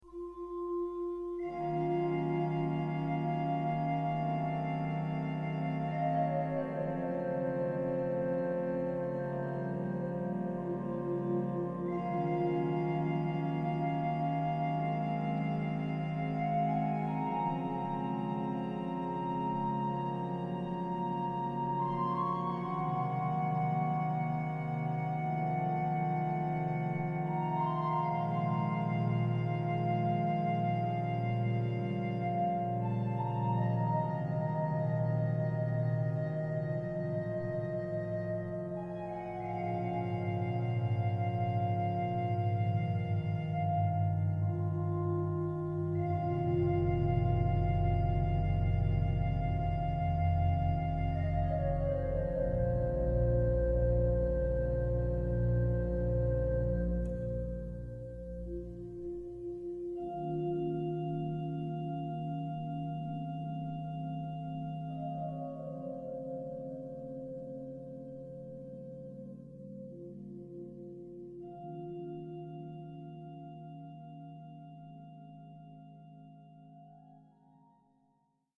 1st Inauguration Concert